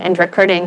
synthetic-wakewords
ovos-tts-plugin-deepponies_Kim Kardashian_en.wav